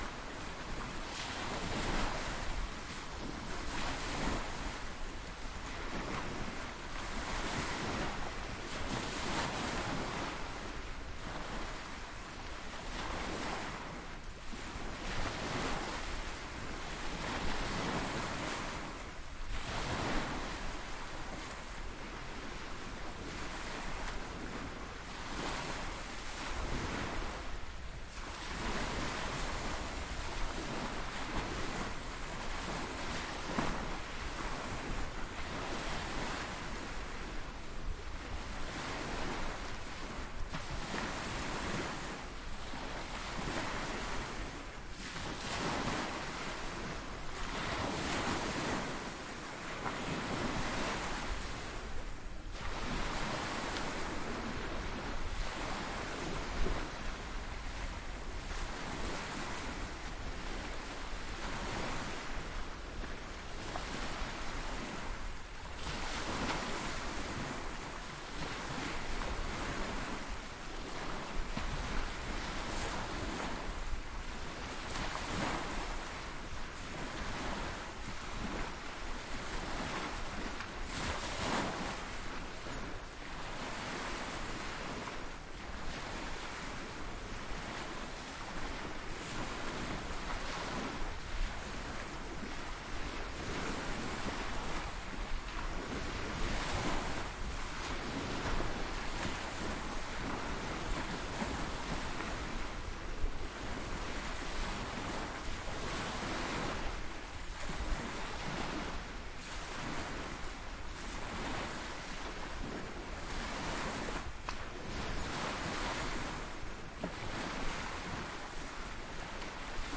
塞萨洛尼基 " 靠近大海的氛围扩展 04
描述：海浪的现场记录距离为5米。 （放大H4n）
Tag: 沙滩 海浪 环境 海浪 海洋 海滨 海岸 现场录音